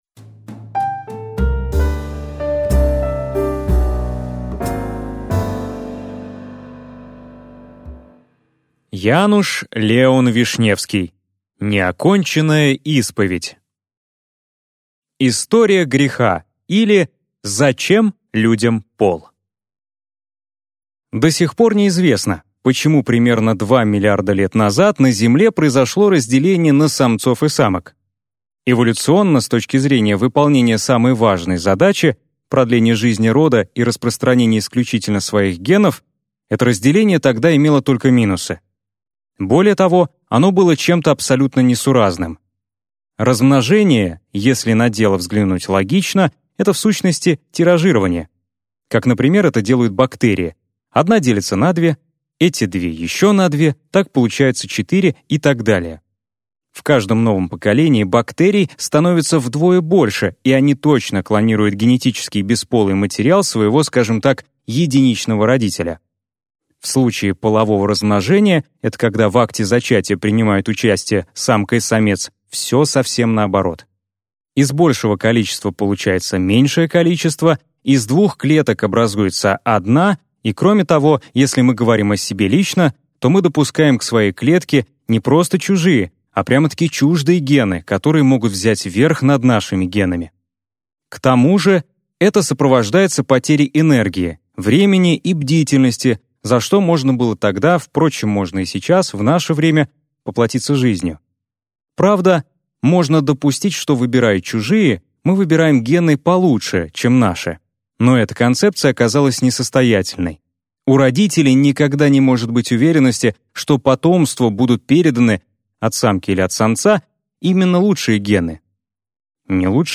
Аудиокнига Неоконченная исповедь | Библиотека аудиокниг